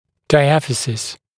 [daɪˈæfɪsɪs][дайˈэфисис]диафиз (центральная цилиндрическая часть длинной трубчатой кости)
diaphysis.mp3